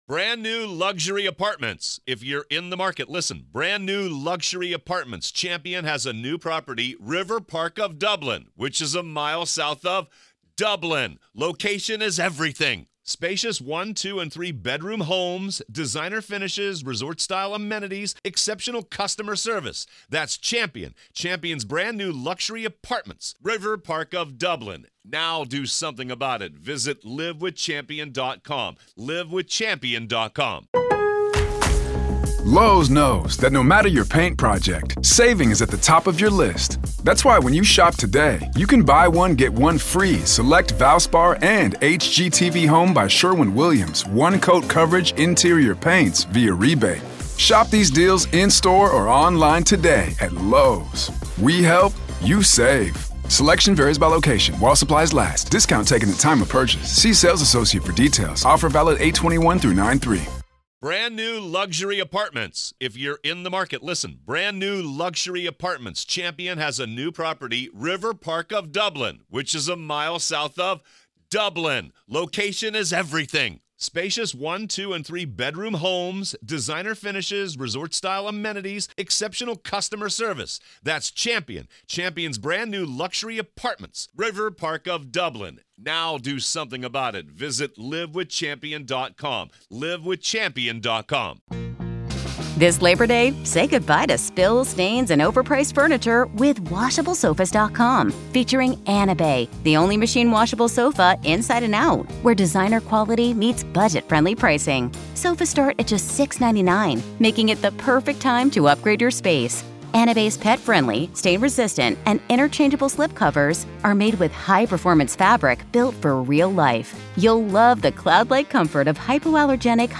We also explore the psychology behind bringing multiple tools to a murder scene—does it suggest overkill, control, or something even darker? This conversation peels back layers of forensic and behavioral complexity that go far beyond the headlines.